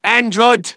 synthetic-wakewords
ovos-tts-plugin-deepponies_Medic_en.wav